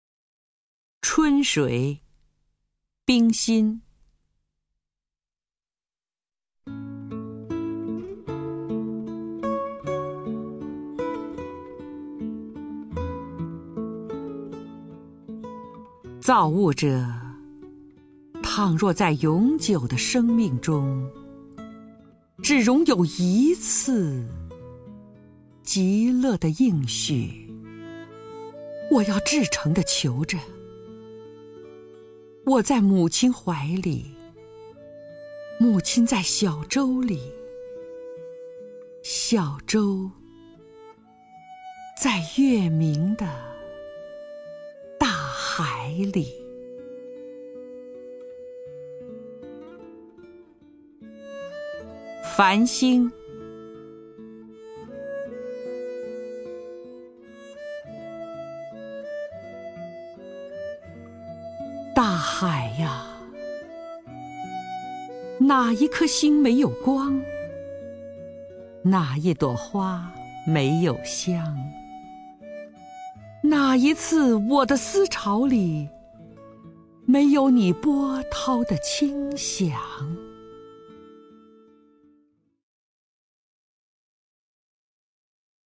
首页 视听 名家朗诵欣赏 张筠英
张筠英朗诵：《春水（105），繁星（131）》(冰心)